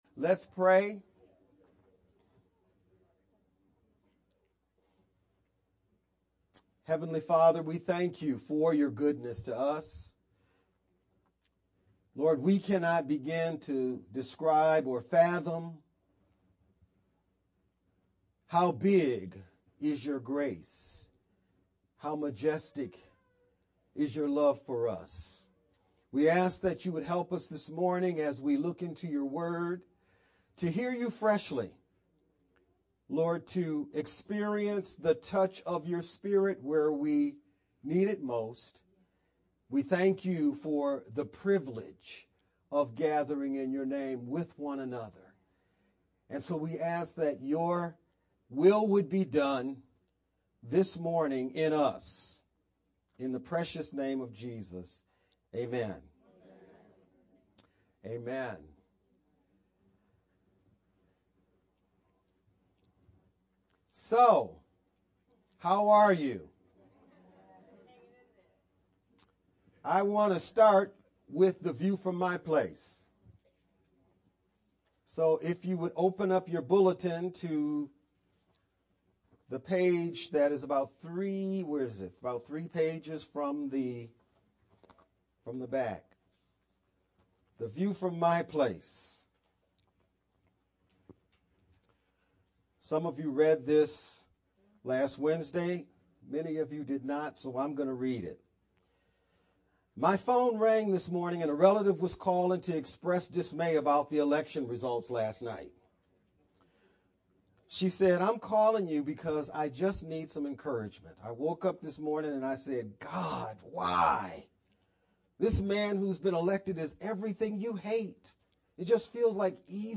VBCC-Sermon-edited-11-10-Sermon-only_Converted-CD.mp3